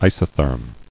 [i·so·therm]